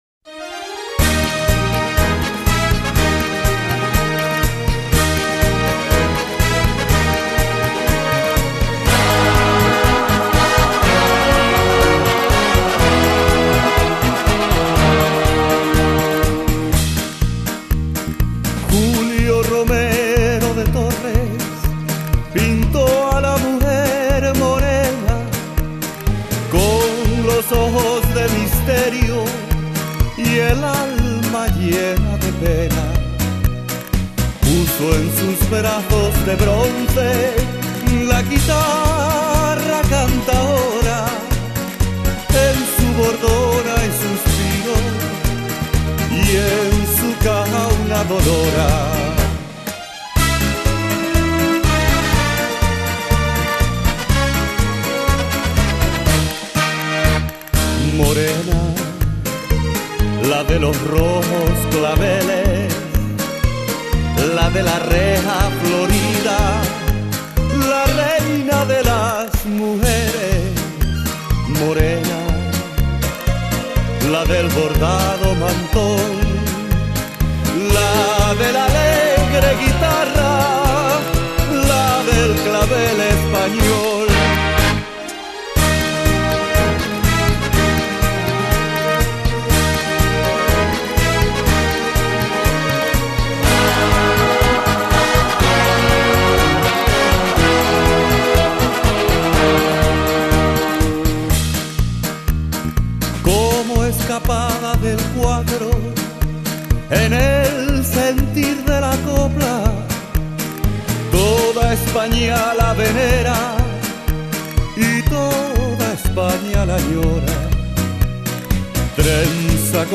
01 Paso Double